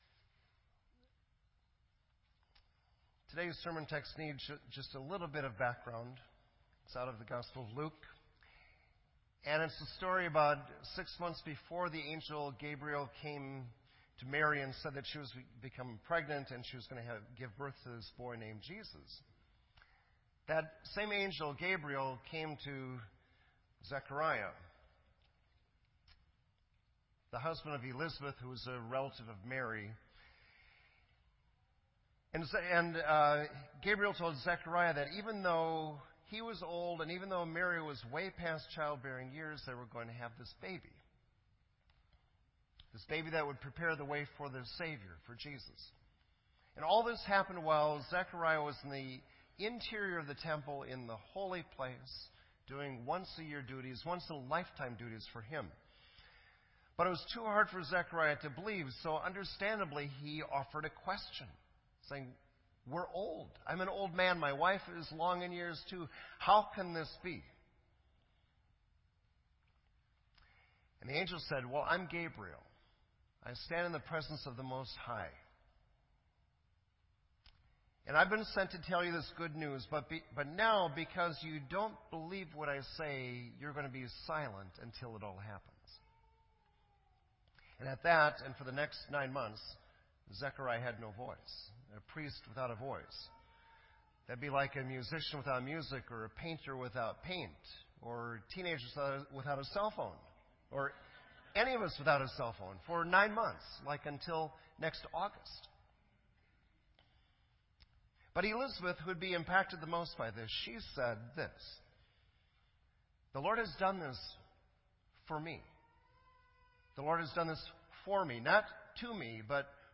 This entry was posted in Sermon Audio on December 11